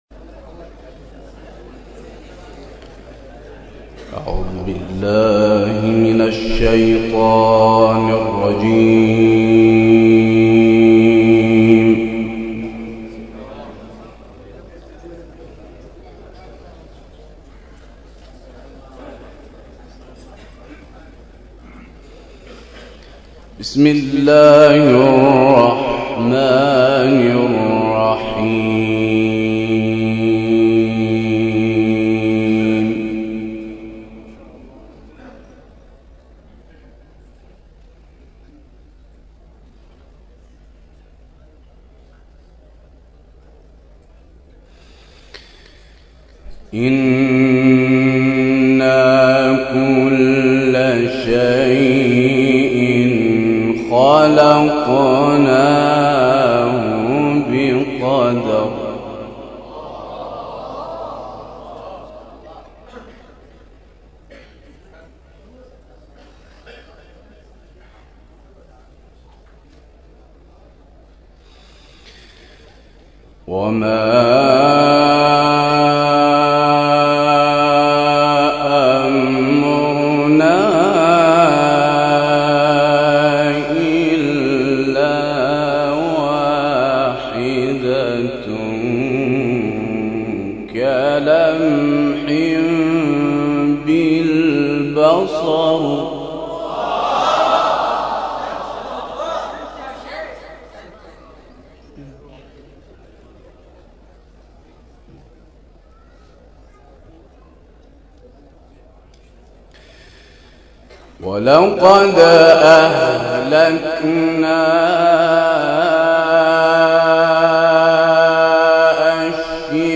این برنامه ها از روز 17 ربیع الاول و مصادف با میلاد پیامبر اکرم(ص) در شهرک صدر بغداد شروع و با تلاوت در حرم مطهر حضرت اباعبدالله الحسین(ع) در روز دوشنبه پایان یافت.